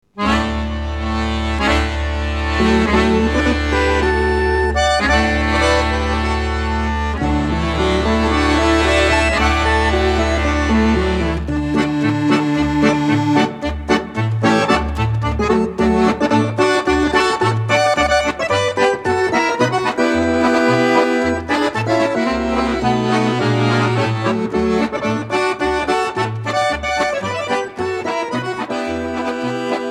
Recorded at Stebbing Recording Studios